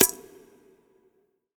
Conga SwaggedOut.wav